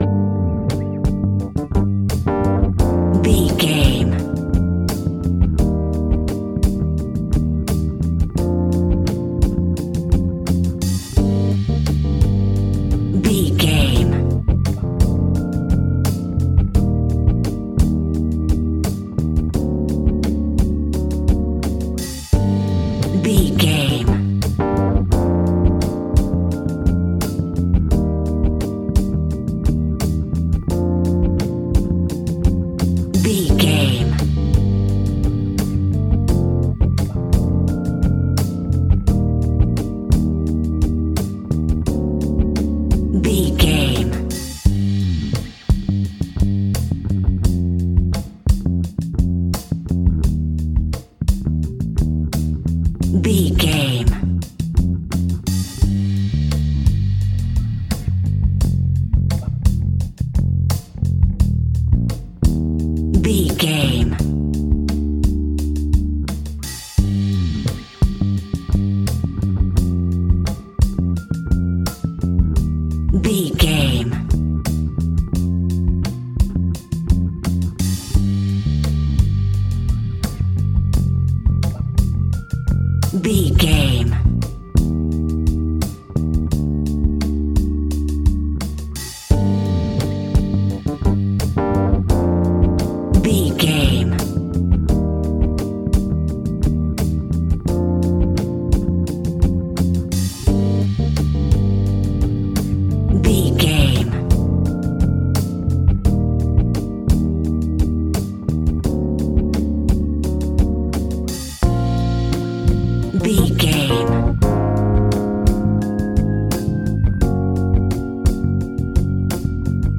In-crescendo
Thriller
Aeolian/Minor
tension
ominous
dark
suspense
haunting
eerie
Horror Pads
Horror Synths
Horror Synth Ambience